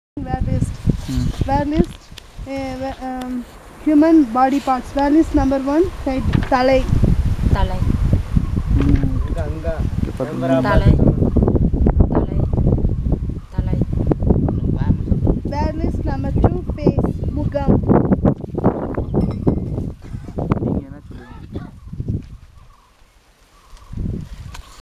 Elicitation of words about human body parts - Part 1